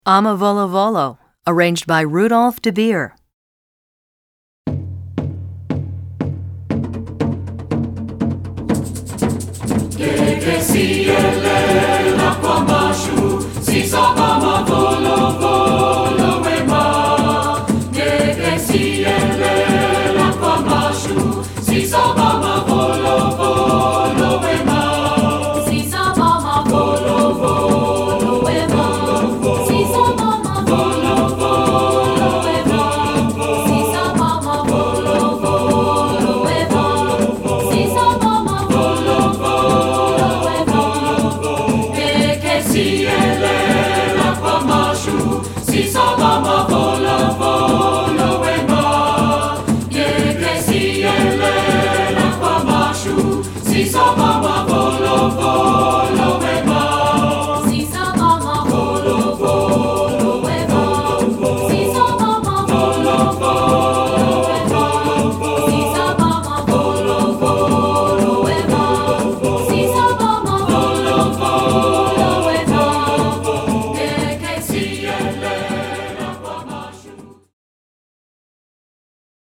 Voicing: SA(T)B